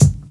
Kick
silky-bass-drum-single-hit-d-key-40-XLg.wav